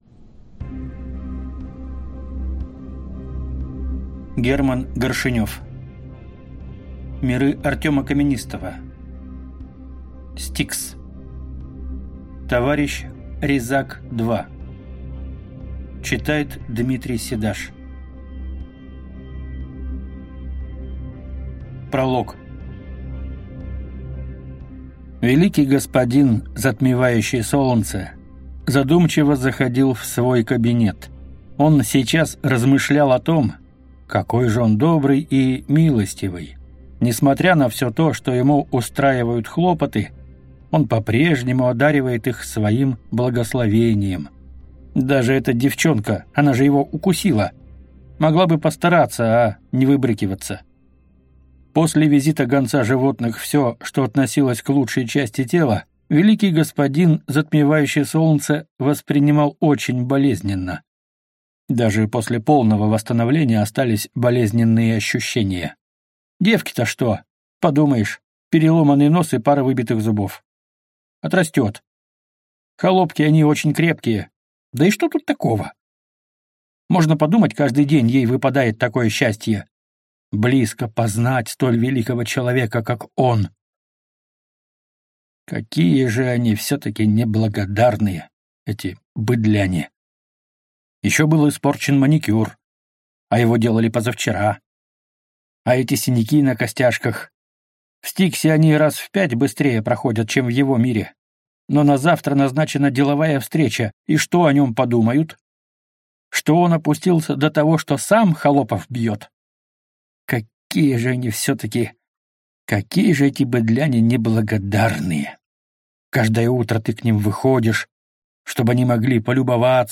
Аудиокнига S-T-I-K-S. Товарищ Резак 2 | Библиотека аудиокниг